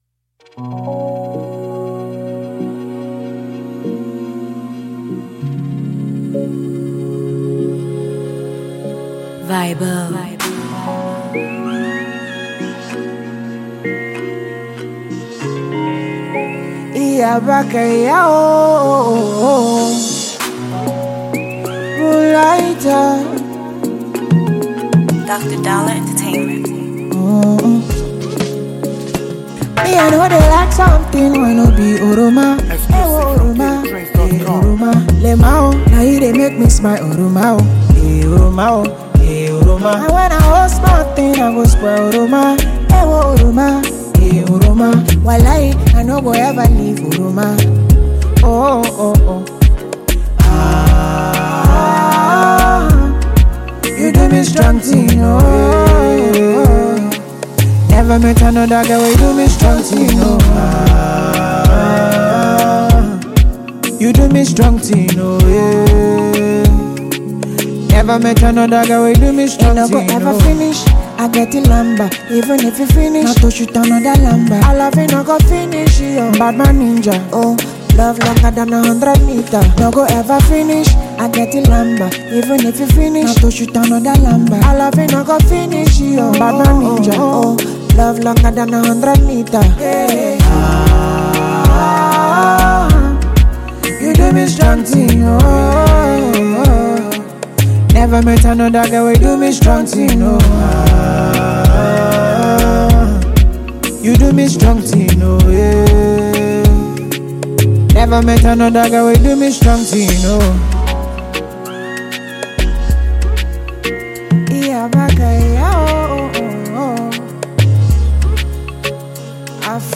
is a chilled groovy Afro pop rhythm